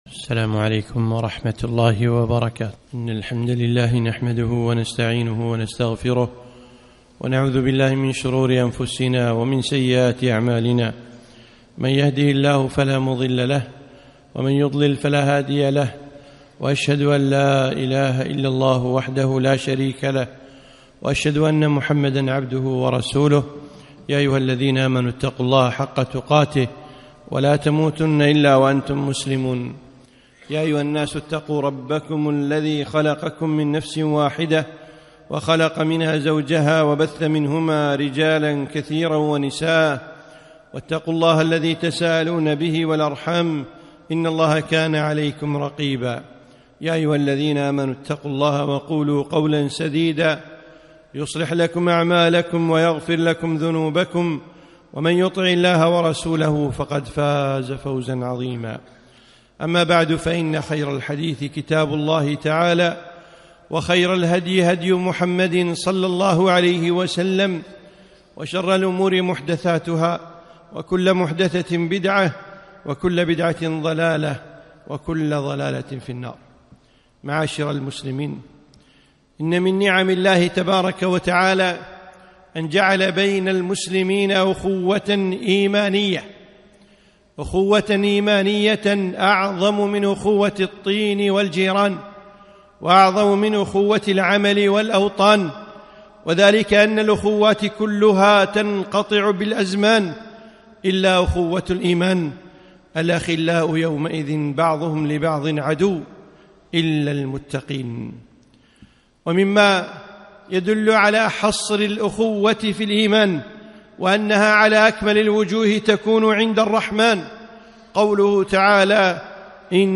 خطبة - أخوة الإيمان